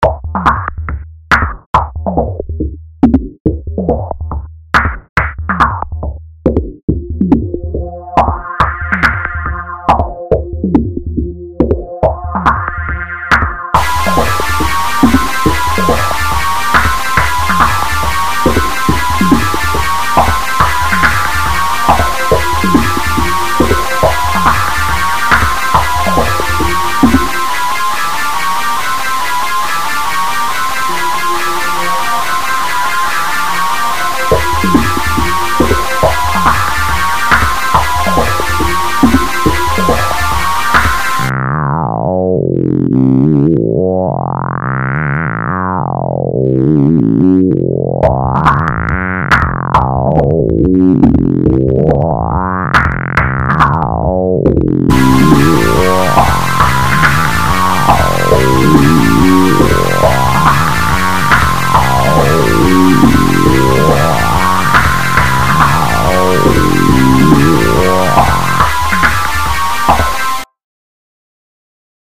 DARK AND GRATING. Not really sleepy music SO MUCH.